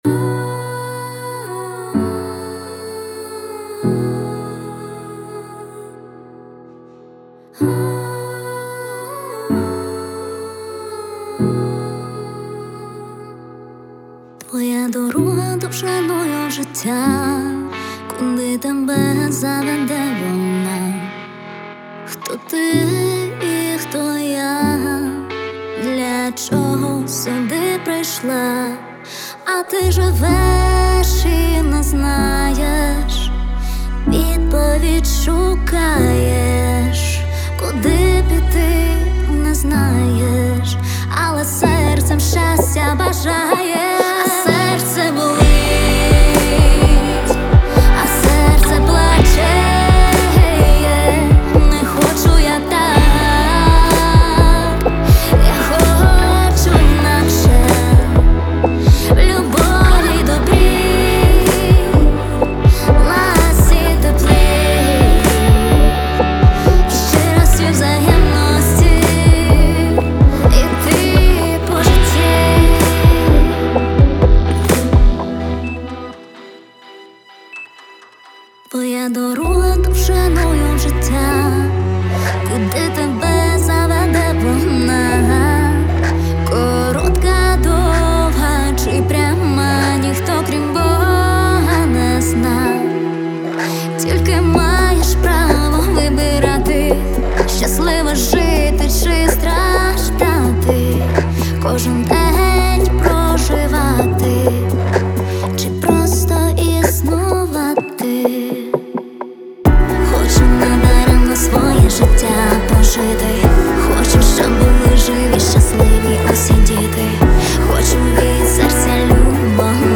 Стиль: Рор лірика